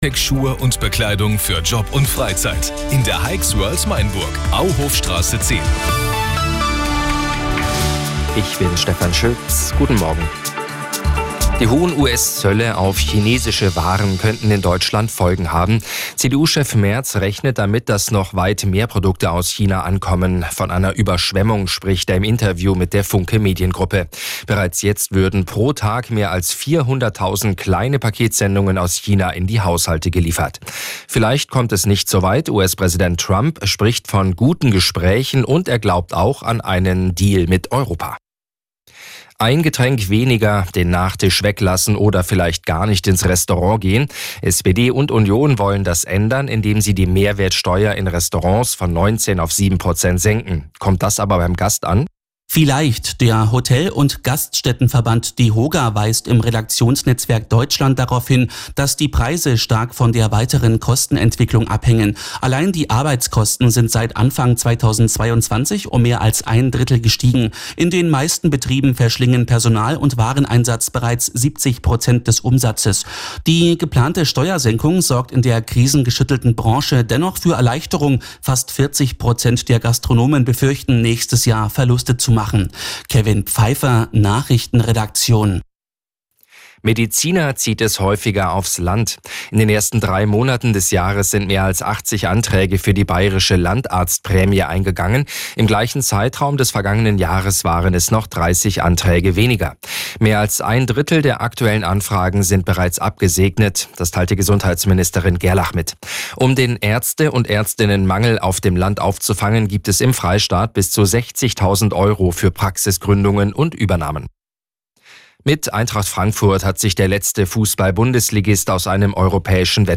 Die Radio Arabella Nachrichten von 9 Uhr - 09.06.2025